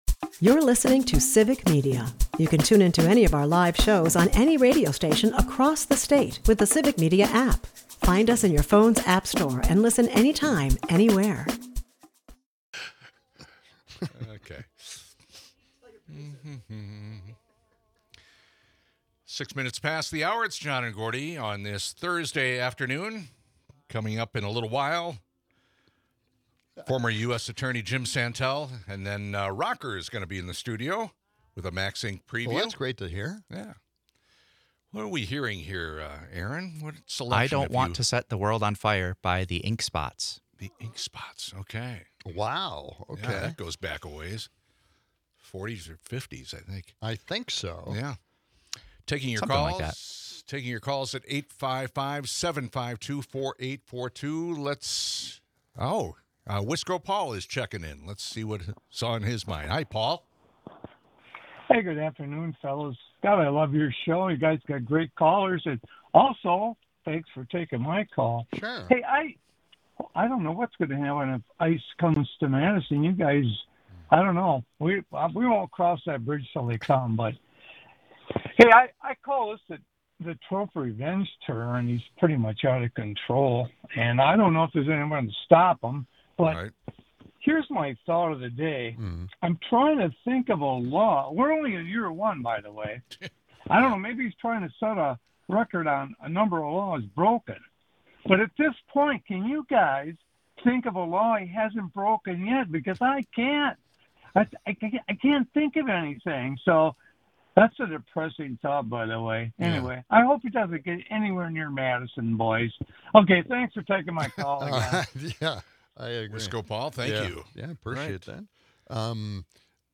At the bottom of the hour, former US Attorney Jim Santelle makes his weekly visit to cover the most important legal news of the day. President Trump today announced that he seeks to invoke the Insurrection Act and send the military in to respond to anti-ICE protests in Minneapolis. Jim weighs in on how this may conflict with other laws in the US.